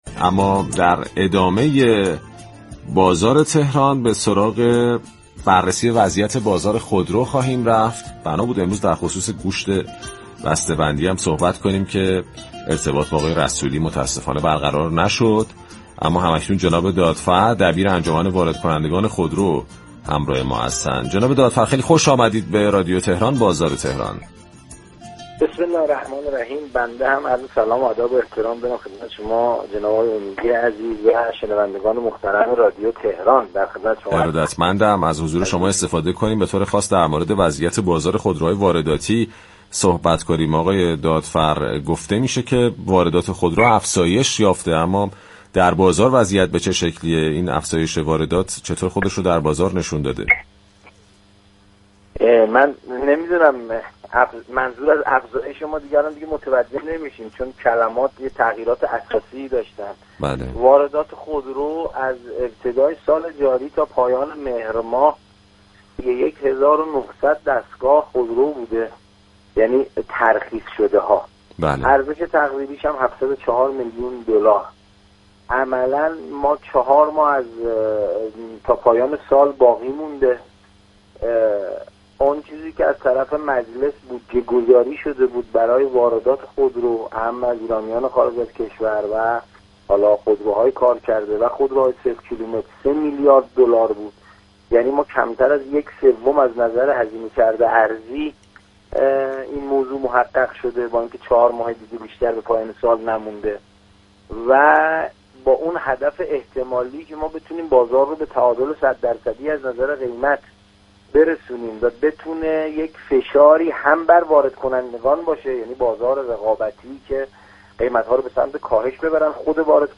در گفتگو با برنامه «بازار تهران» رادیو تهران